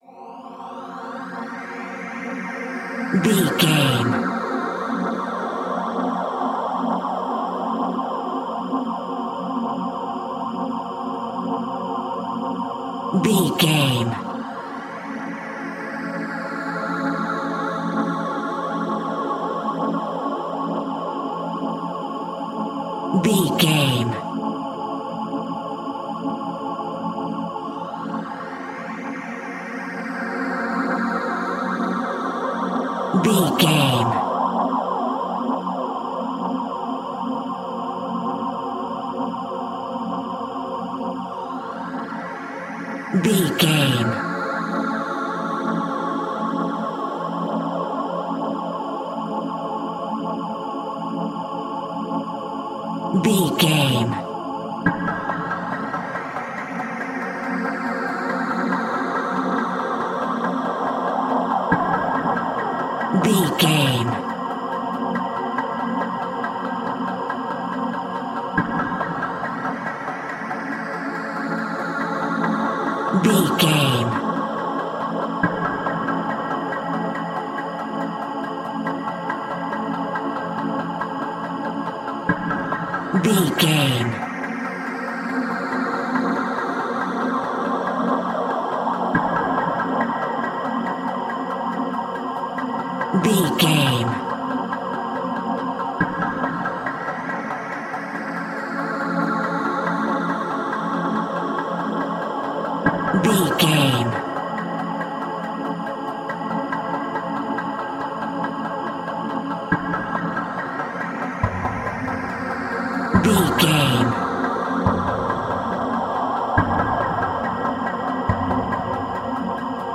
Scary Corridors.
In-crescendo
Thriller
Aeolian/Minor
ominous
eerie
horror music
Horror Pads
horror piano
Horror Synths